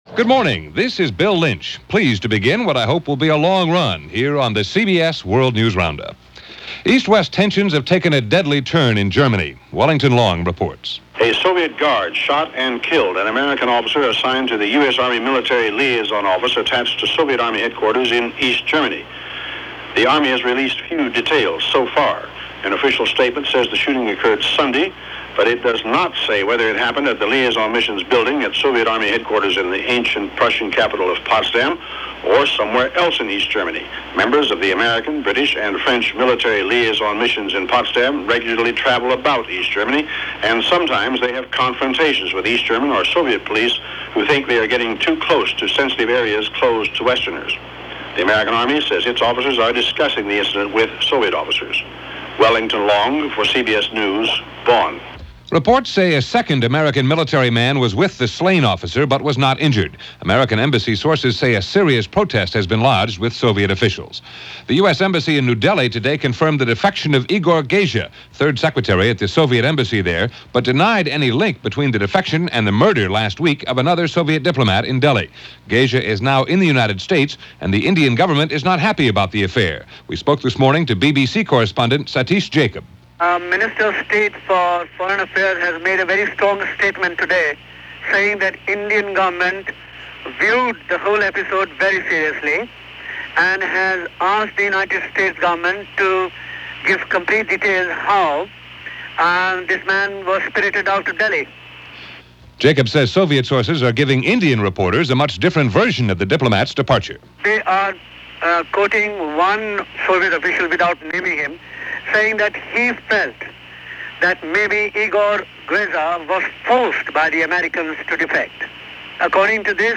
At the time of this broadcast, American Officers were discussing the incident with Soviet Officers, and declined to elaborate any further.
All that, and a lot more for this March 25th in 1985 as presented by The CBS World News Roundup